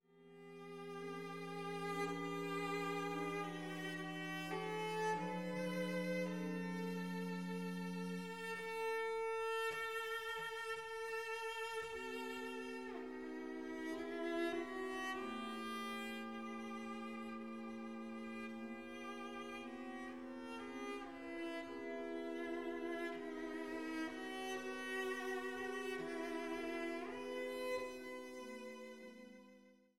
für Violoncello und Orgel